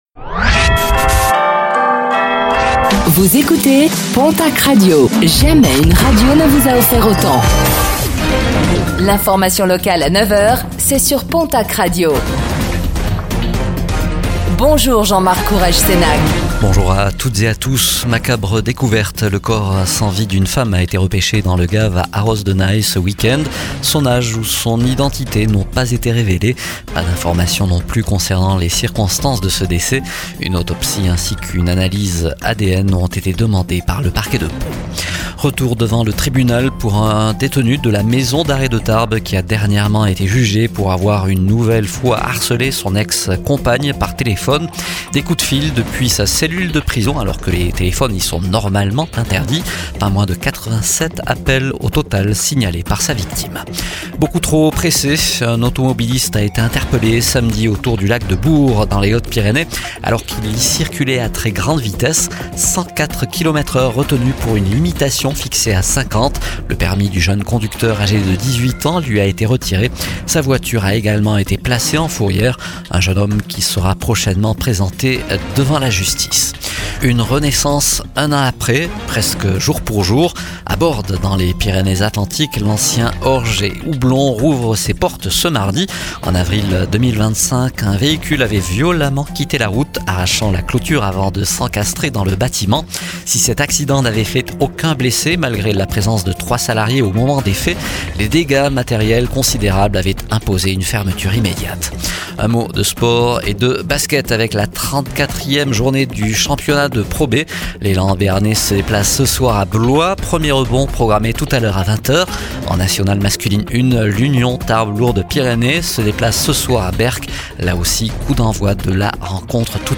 Réécoutez le flash d'information locale de ce mardi 21 avril 2026